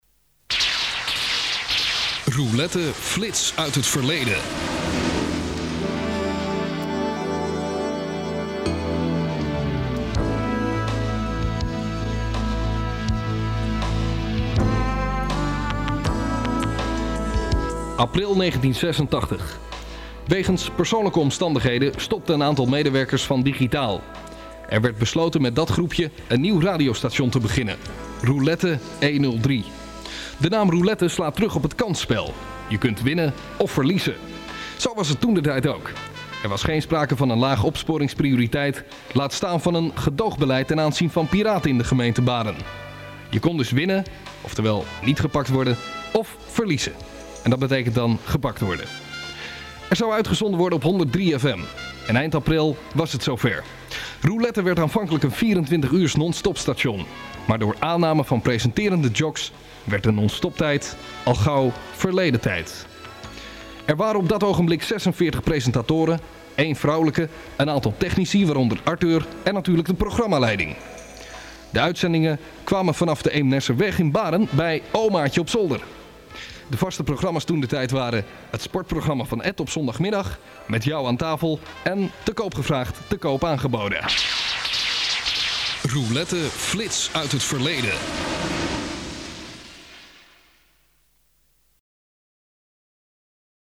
Alles op cassette en spoelen (bandrecorders)